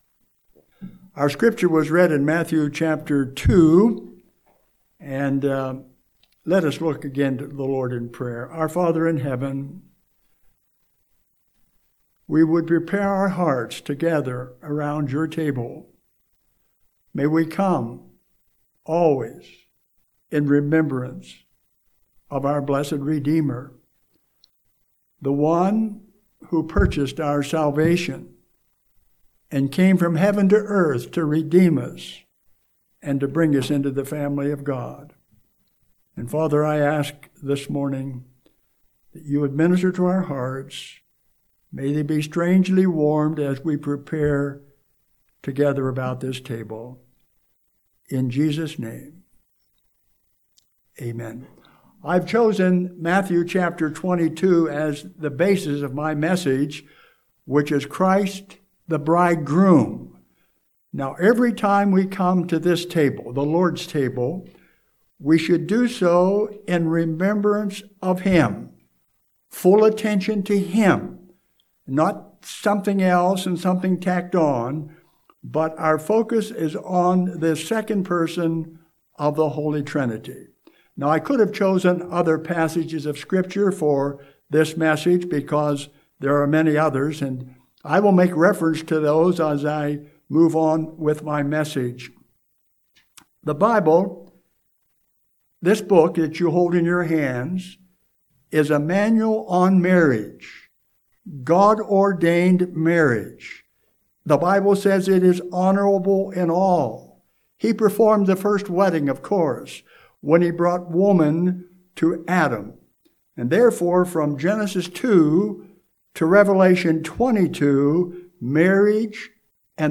Home › Sermons › October 4, 2020
Sunday Morning Sermon